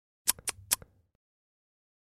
Цокают неодобрительно
Тут вы можете прослушать онлайн и скачать бесплатно аудио запись из категории «Анатомия, тело человека».